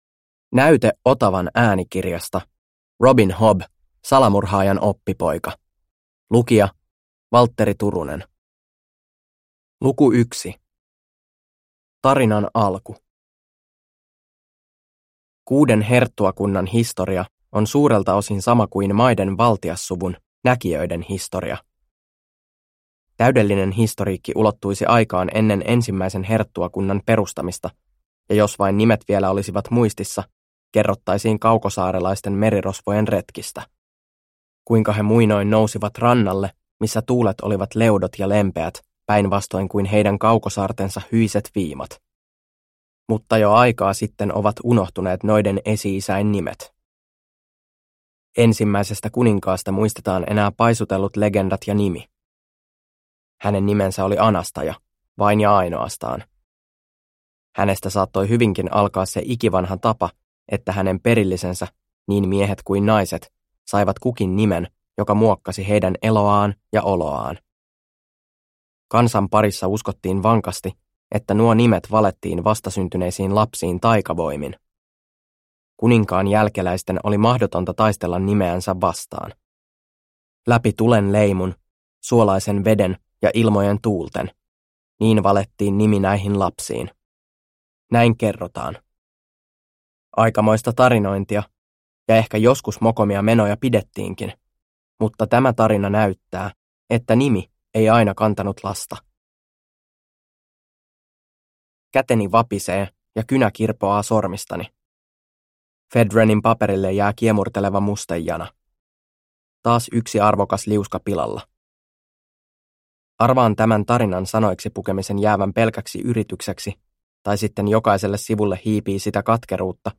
Salamurhaajan oppipoika – Ljudbok – Laddas ner